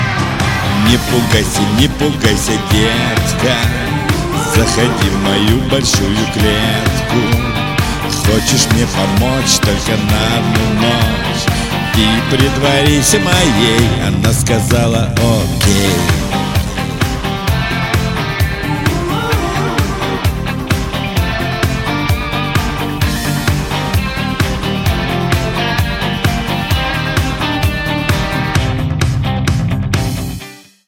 • Качество: 192, Stereo
поп
веселые